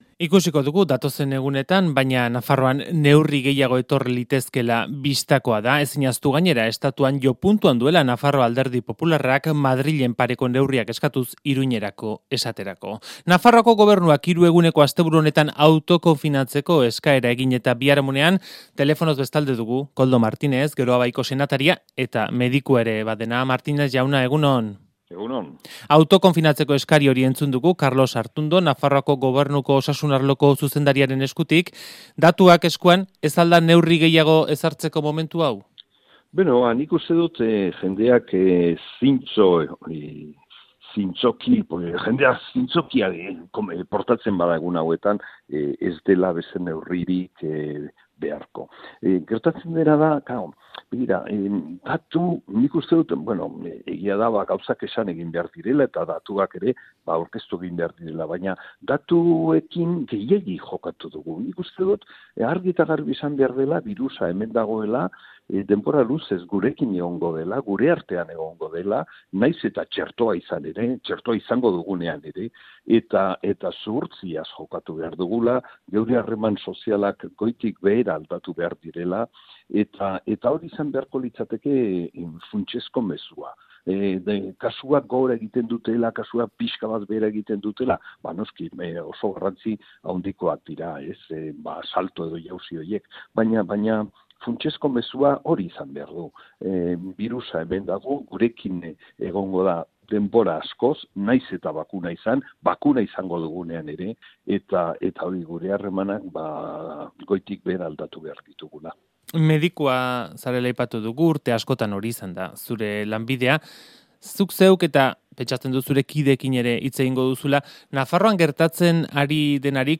Audioa: Koldo Martinez Goiz Kronikan koronabirusaren datuen arira elkarrizketan